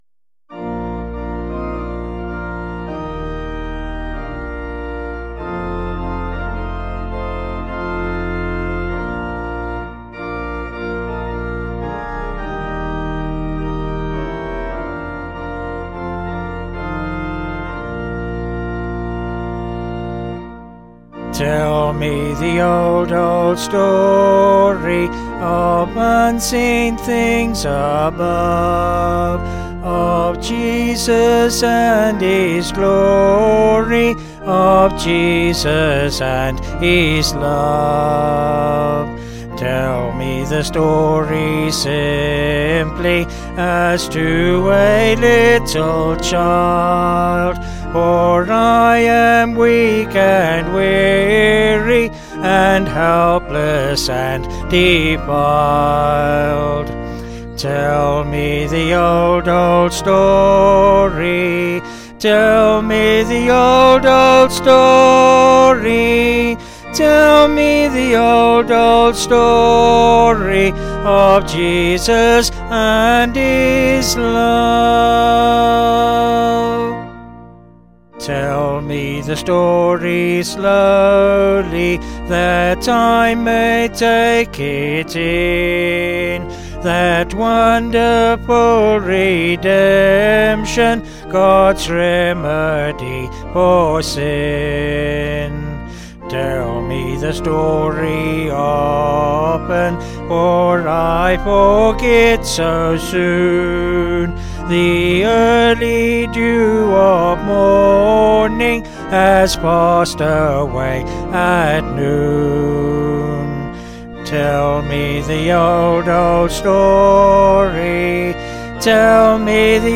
Vocals and Organ   705.4kb Sung Lyrics